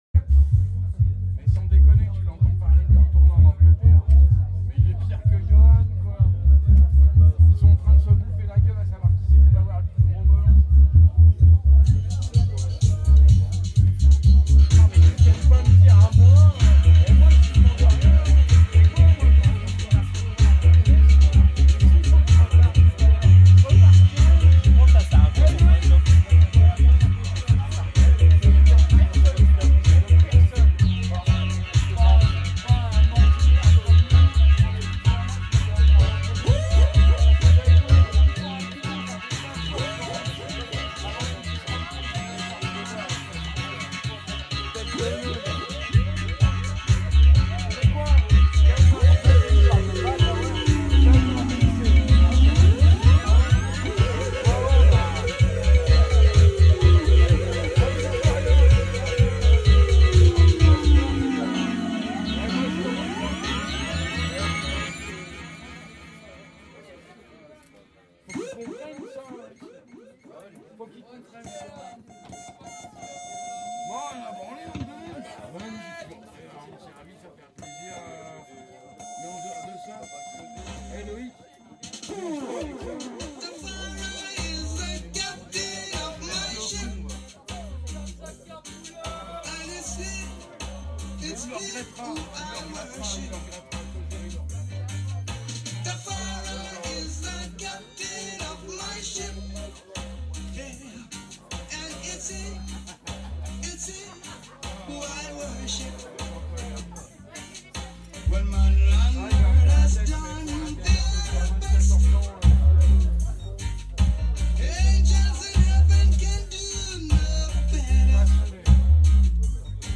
Roots & Original pressings ina di area